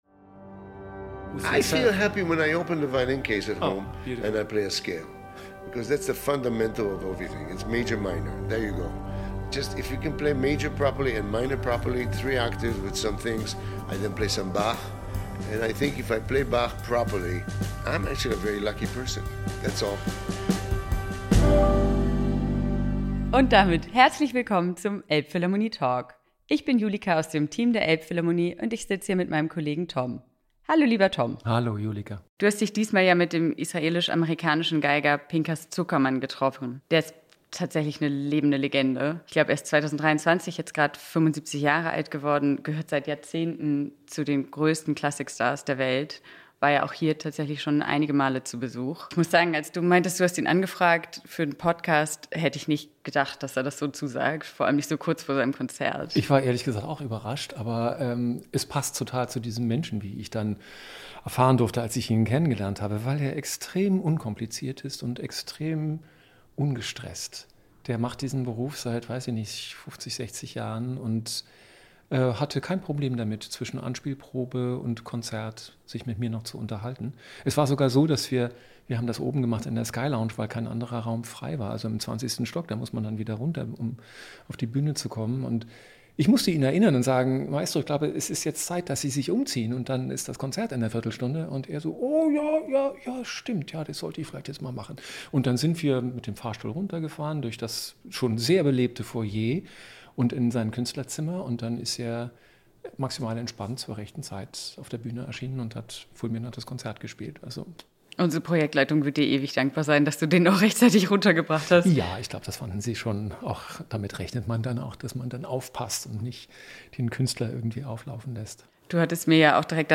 Der Weltklasse-Geiger Pinchas Zukerman über seine Ausbildung und das Rezept für einen Turbo-Sound. Man muss als Musiker schon sehr die Ruhe weg haben, wenn man sich im kleinen Zeitfenster zwischen Anspielprobe und Konzert noch eben ins 20. Stockwerk der Elbphilharmonie entführen lässt, um dort einen Podcast aufzunehmen.
Ehe er in der Tripelfunktion als Geiger, Bratscher und Dirigent im Großen Saal vor das English Chamber Orchestra trat, um Musik von Hindemith, Mozart und Telemann aufzuführen, gab er leutselig, entspannt und zugleich sehr engagiert Auskunft über sich und darüber, wie er die Welt und die Musik sieht.